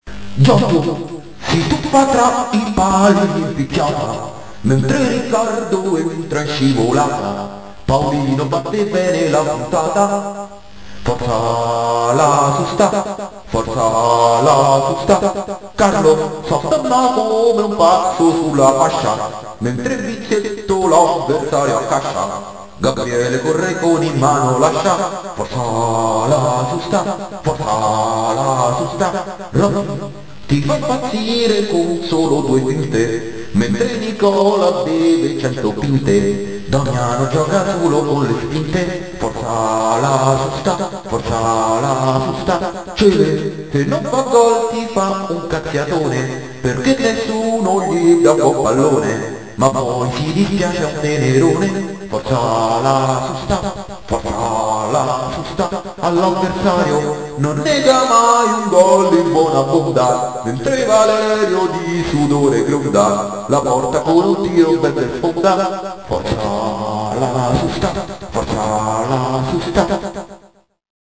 riverbero